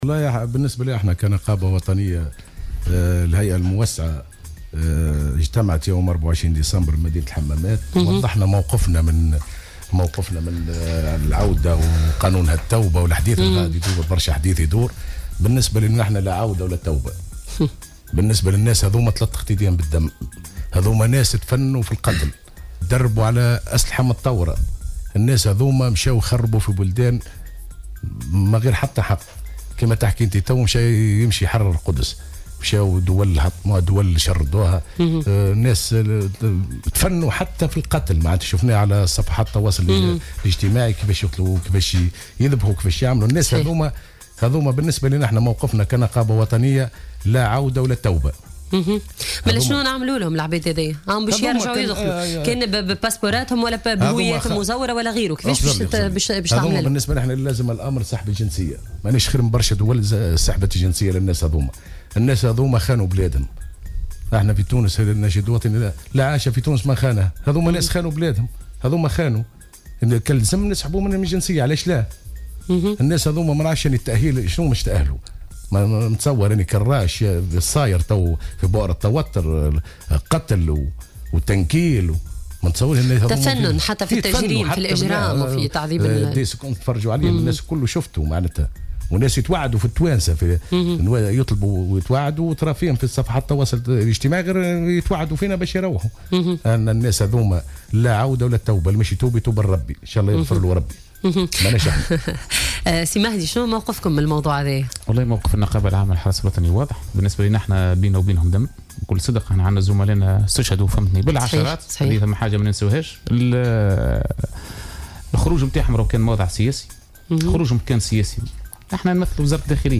في حوار مع الجوهرة أف أم، خلال برنامج "علاش هكا؟"